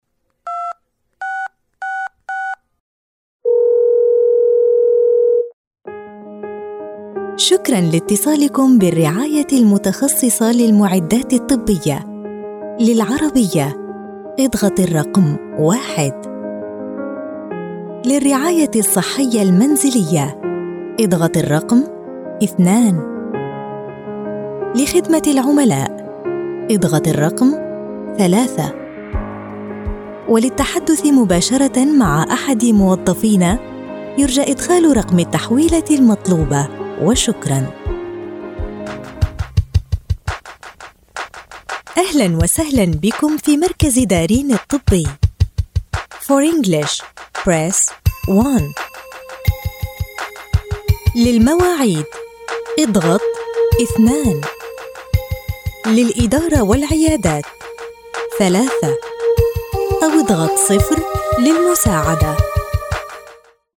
This setup ensures prompt access to her broadcast-quality booth and equipment, enabling her to deliver exceptional voice-over services efficiently.
She effortlessly transitions between serious and lighthearted tones, adapting her delivery to suit the subject matter.
IVR Demo